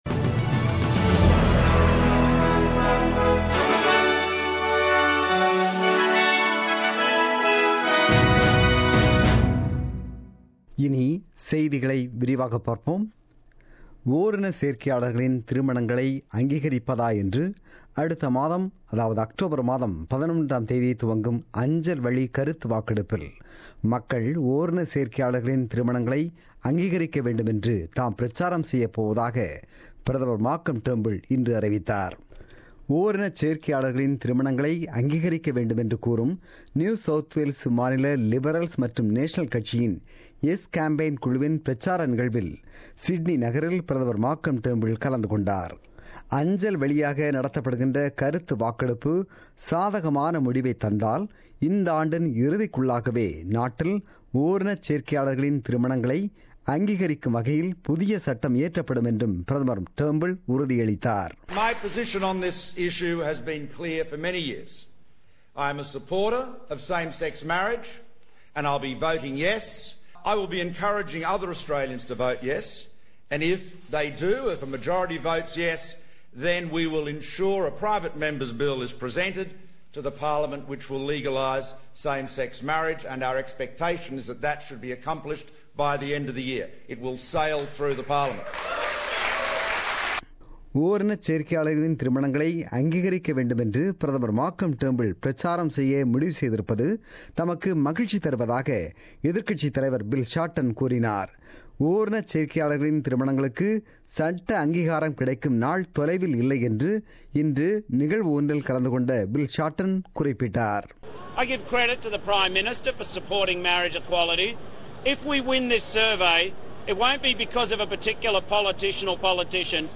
The news bulletin broadcasted on 10 September 2017 at 8pm.